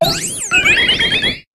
Cri de Floette Fleur Éternelle dans Pokémon HOME.
Cri_0670_Éternelle_HOME.ogg